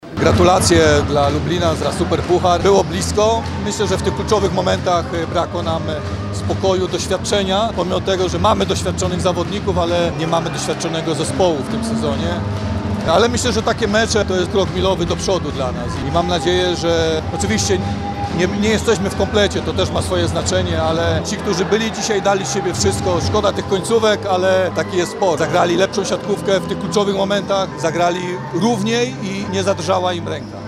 – skomentował występ swoich siatkarzy trener JSW Jastrzębskiego Węgla, Andrzej Kowal.